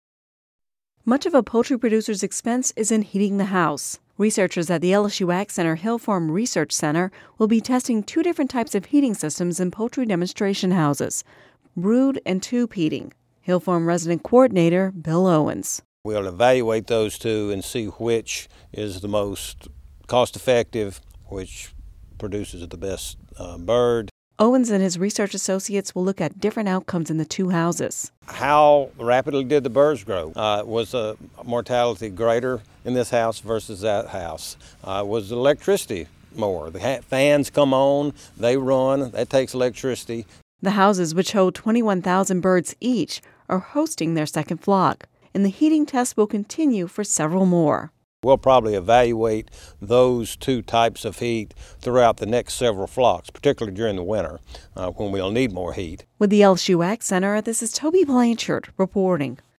(Radio News 11/08/10) Much of the expenses for poultry producers come from heating the houses, which is critical for young chicks. Researchers at the LSU AgCenter's Hill Farm Research Station will be testing two different types of heating systems in poultry demonstration houses – brood and tube heating.